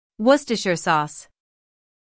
IPA: /ˈwʊs.tər.ʃər sɔːs/.
worcestershire-sauce-us.mp3